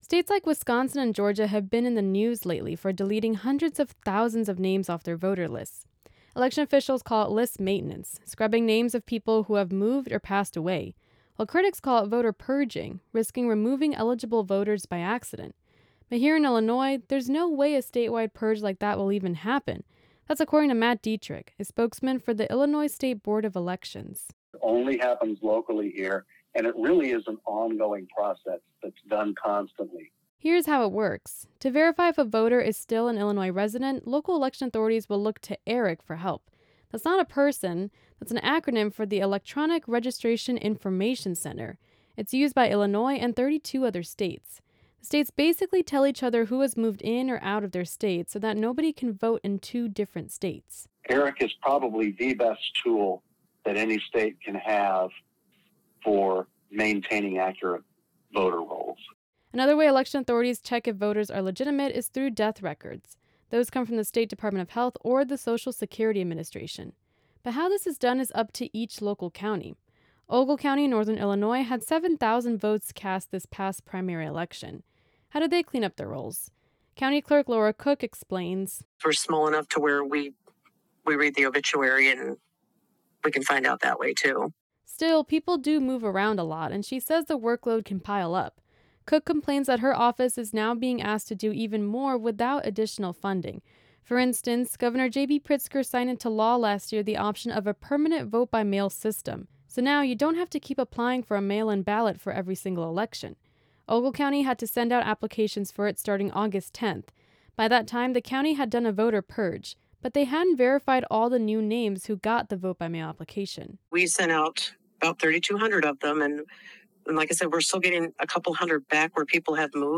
This interview has been edited for brevity and readability.